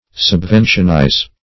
Meaning of subventionize. subventionize synonyms, pronunciation, spelling and more from Free Dictionary.